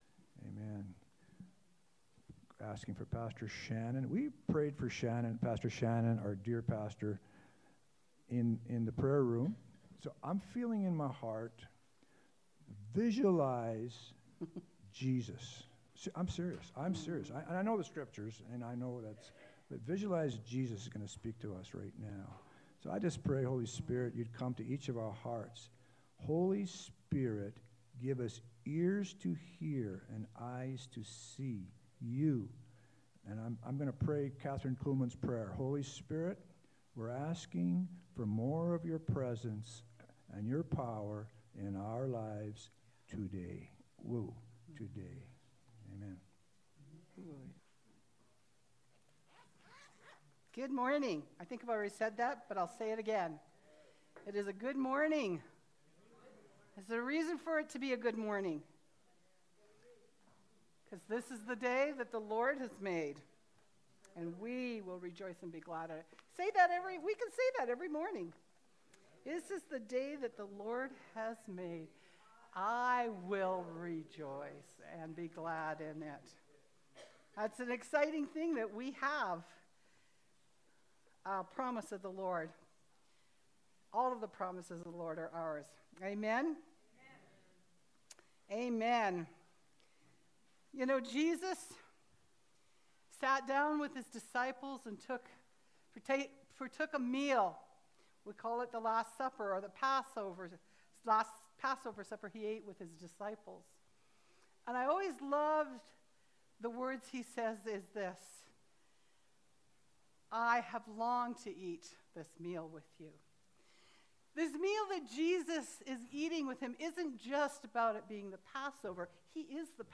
September-21-Sermon-Only-2.mp3